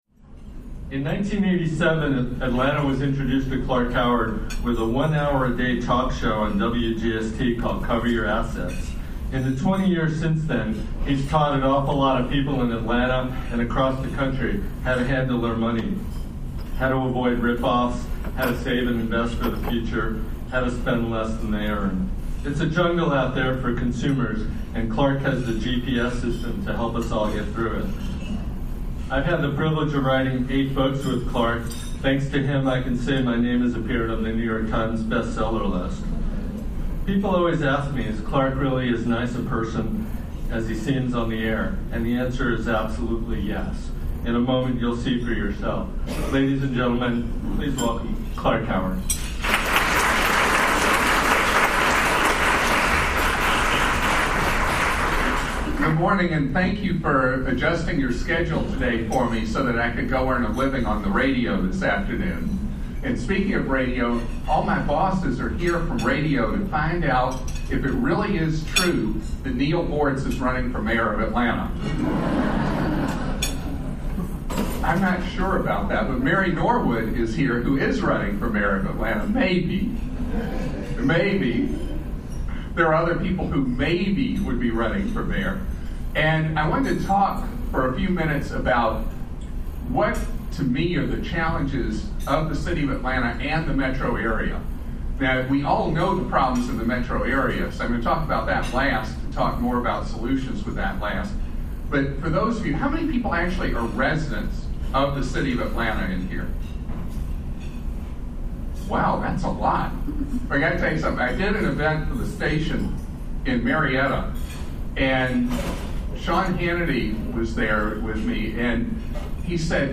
Show notes On Friday, September 28, Consumer Advocate Clark Howard spoke to the Atlanta Press Club about his views on education, homelessness and government. Howard also talked about rumors on his running for Mayor.